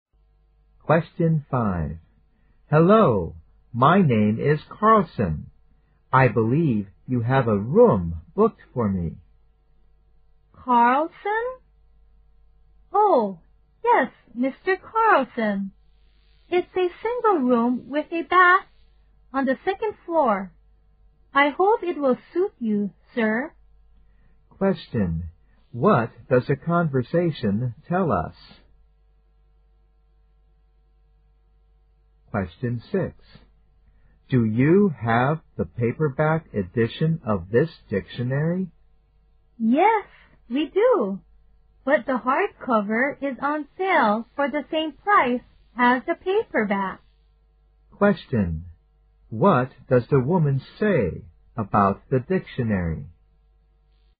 在线英语听力室146的听力文件下载,英语四级听力-短对话-在线英语听力室